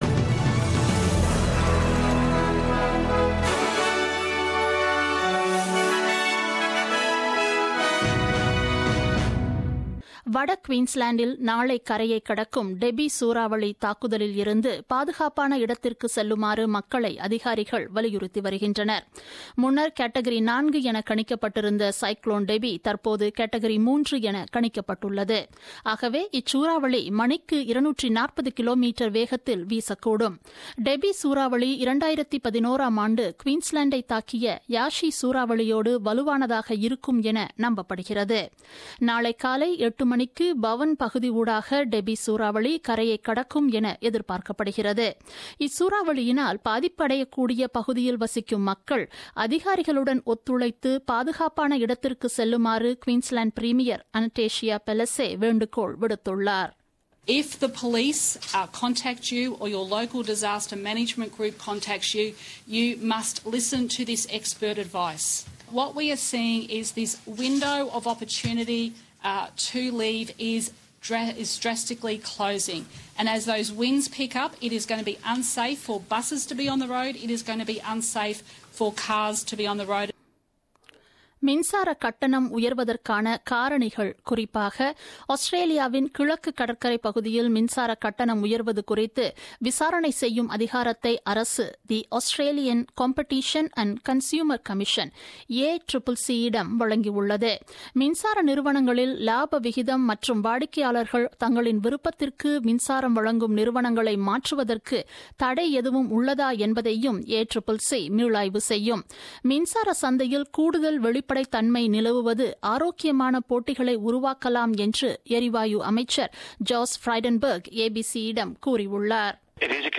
The news bulletin broadcasted on 27th March 2017 at 8pm.